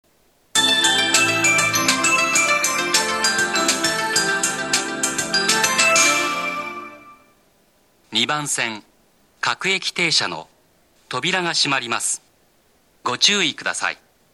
2番線急行発車放送
hirakatashi-track2-d_ex.mp3